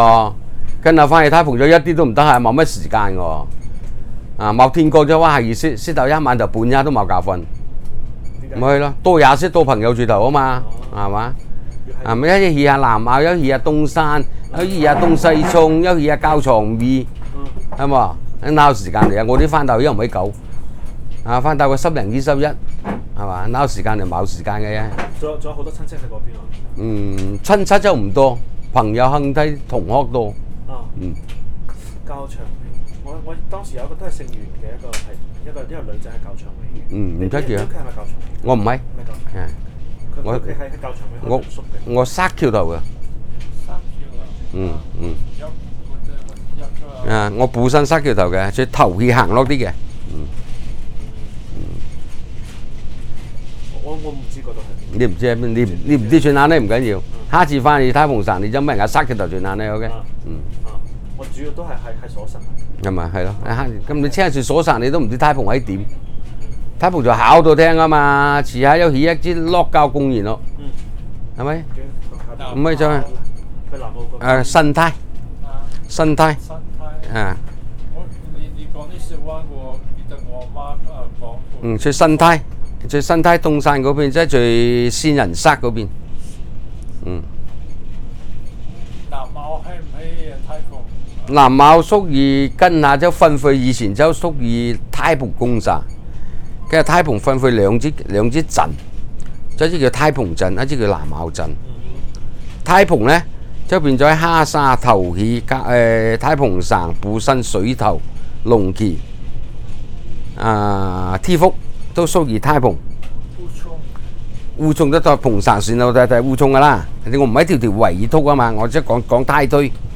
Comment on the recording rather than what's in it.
digital wav file recorded at 44.1 kHz/16 bit on Zoom H2 solid state recorder